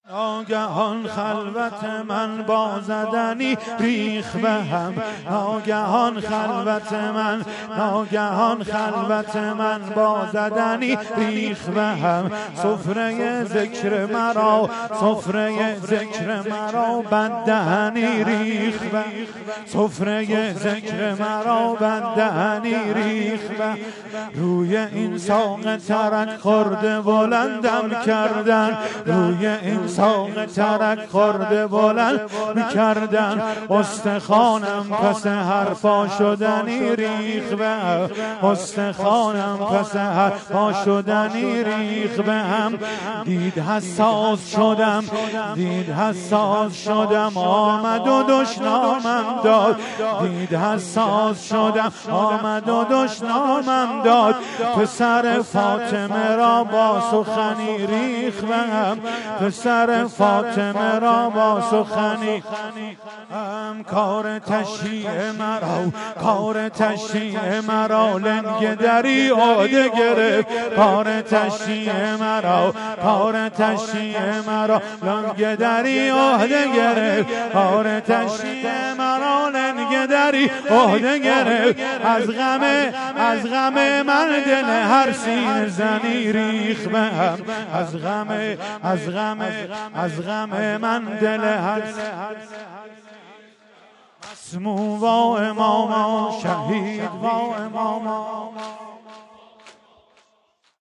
شهادت امام موسی کاظم (ع) 96 - هیئت مصباح الهدی - سنگین - ناگهان خلوت من با زدنی